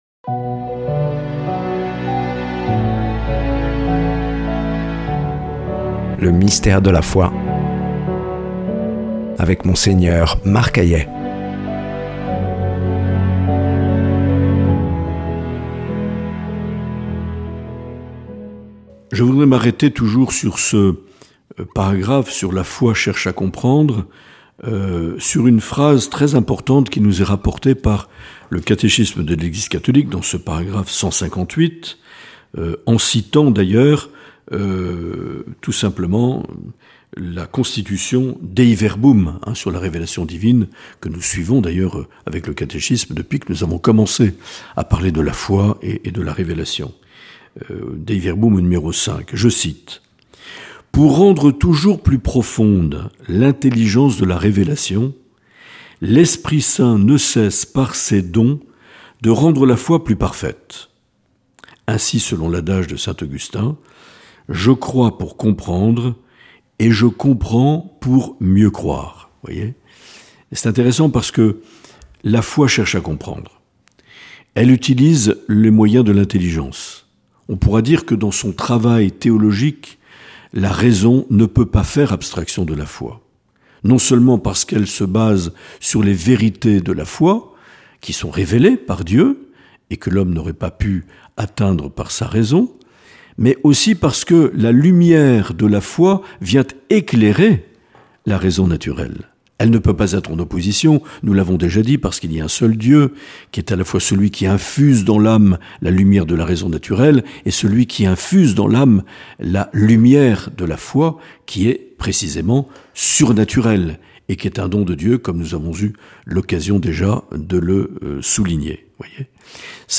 Présentateur(trice)